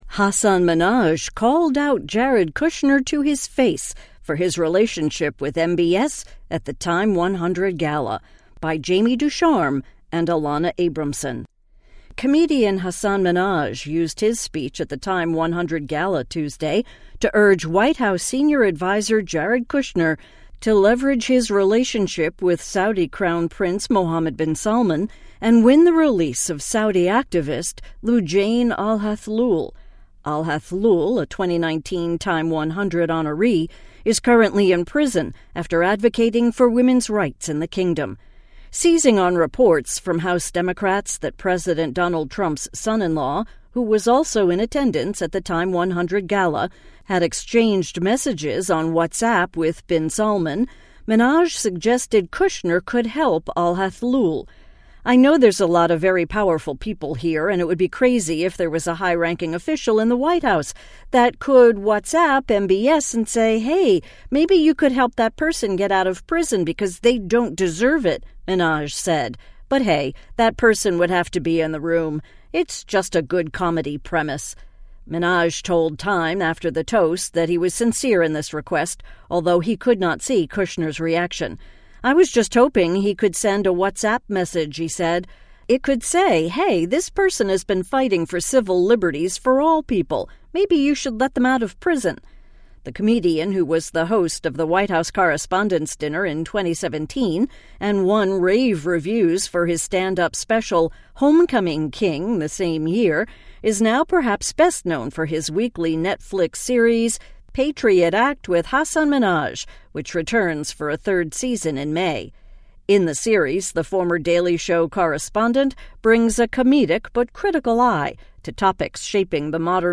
Comedian Hasan Minhaj used his speech at the TIME 100 Gala Tuesday to urge White House Senior Adviser Jared Kushner to leverage his relationship with Saudi Crown Prince Mohammad Bin Salman and win the release of Saudi activist Loujain al-Hathloul.